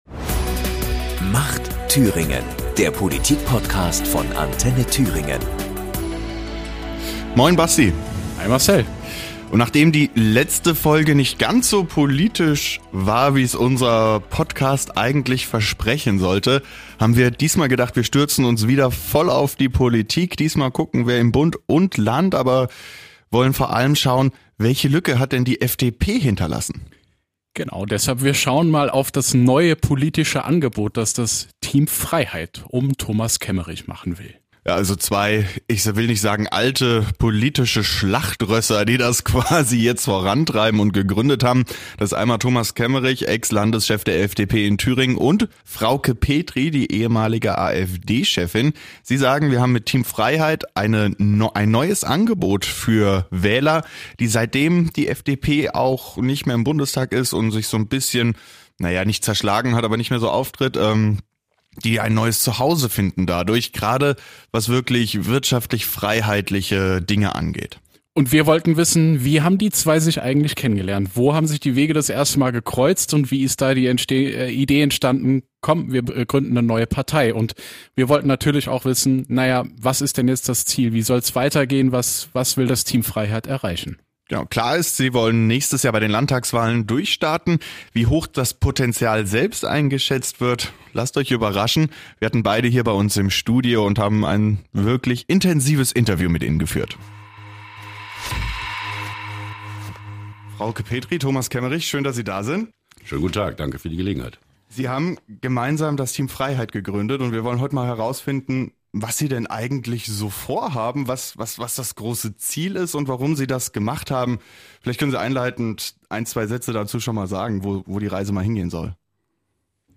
Die Hosts reden mit Kemmerich und Petry auch über Restaurants, die Gäste ablehnen und welche Ministerien abgeschafft werden müssten, wenn es nach Team Freiheit geht.